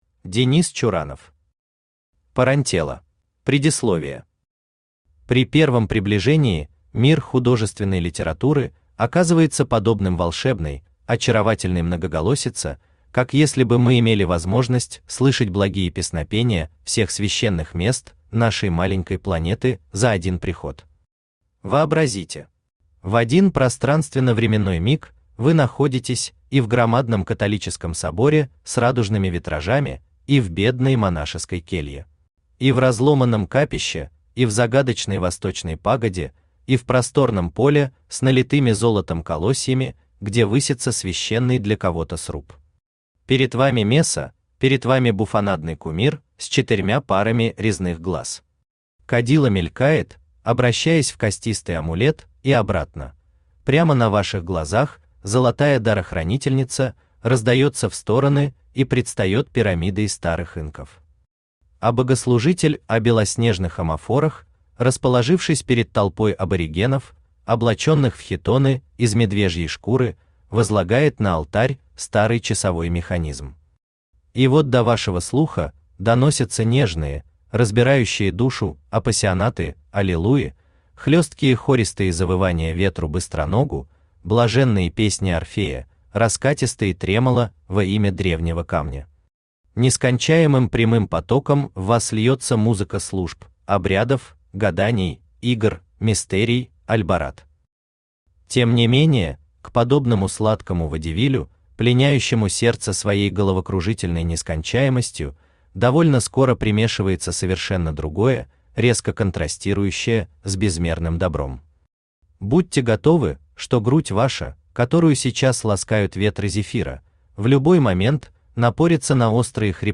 Аудиокнига Парантелла | Библиотека аудиокниг
Aудиокнига Парантелла Автор Денис Чуранов Читает аудиокнигу Авточтец ЛитРес.